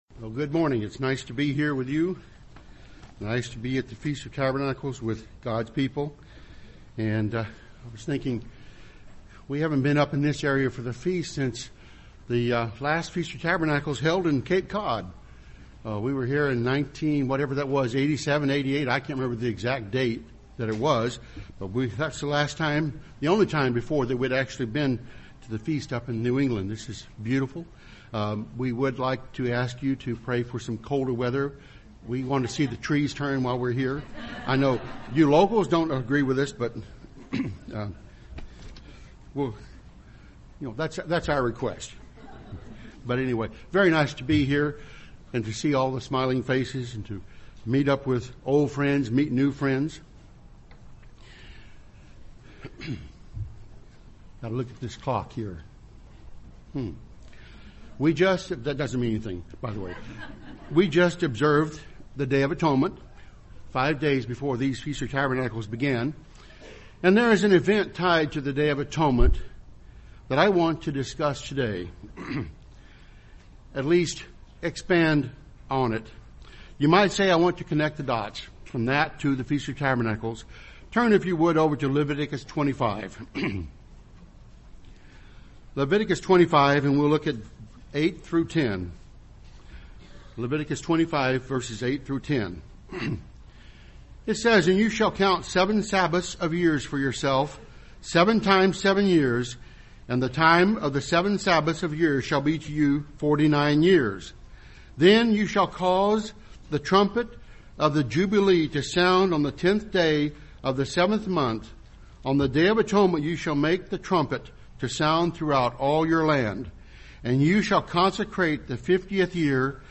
This sermon was given at the Lake George, New York 2012 Feast site.